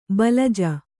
♪ balaja